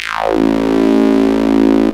tekTTE63037acid-A.wav